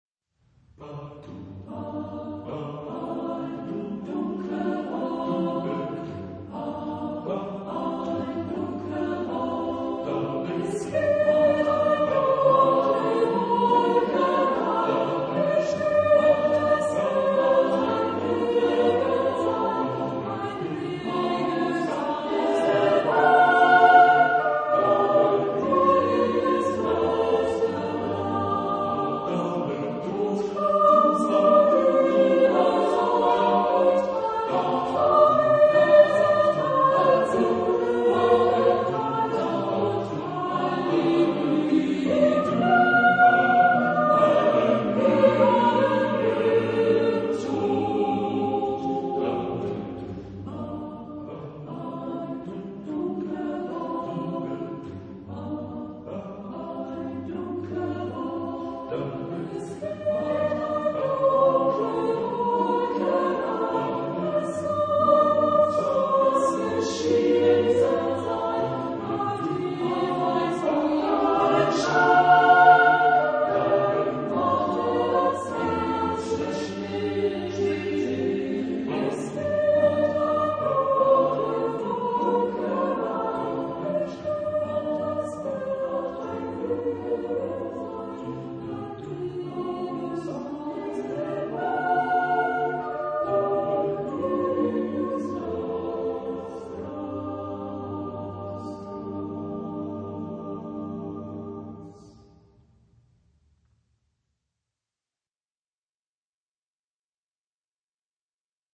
Genre-Stil-Form: Volkslied ; Madrigal ; weltlich
Chorgattung: SATTB  (5 gemischter Chor Stimmen )
Tonart(en): G dorisch